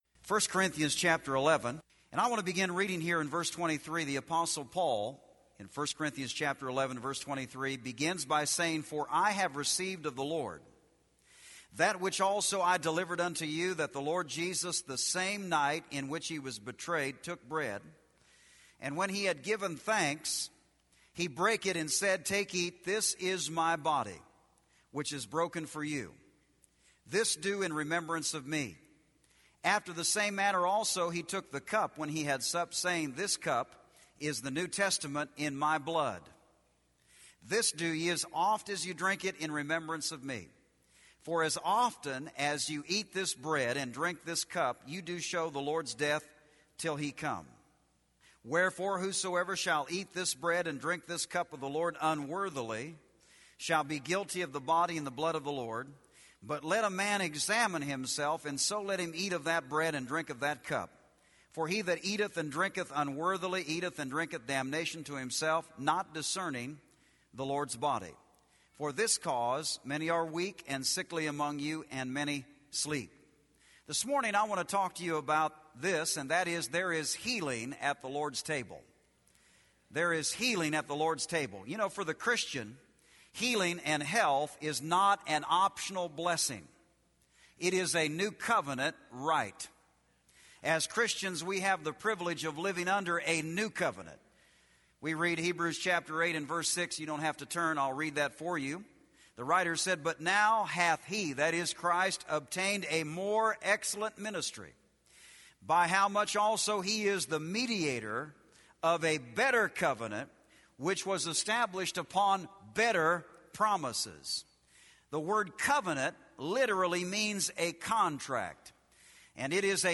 This audio teaching reveals the true meaning of Communion. Through the element of the bread, which represents the body of Jesus that was broken for us, we can receive physical healing and strength for our bodies.